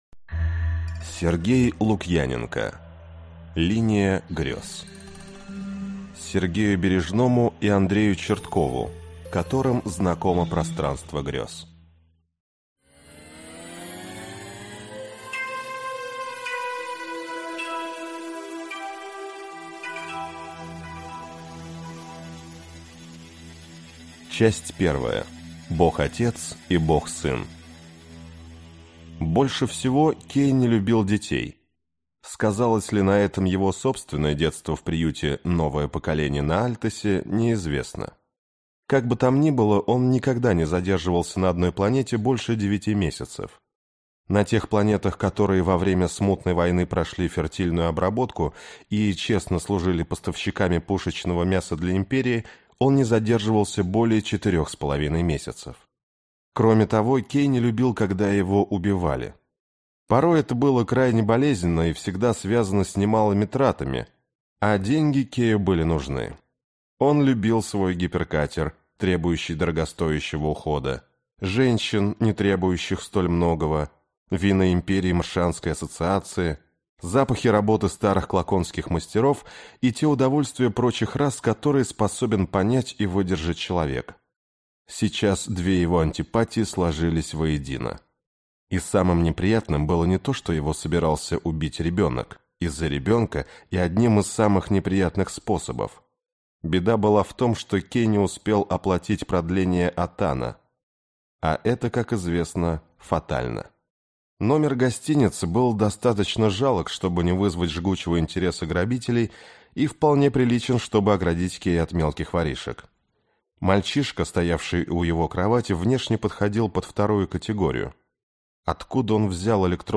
Аудиокнига Линия грез. Императоры иллюзий. Тени снов | Библиотека аудиокниг